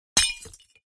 دانلود آهنگ شکستن لیوان و شیشه 3 از افکت صوتی اشیاء
جلوه های صوتی
دانلود صدای شکستن لیوان و شیشه 3 از ساعد نیوز با لینک مستقیم و کیفیت بالا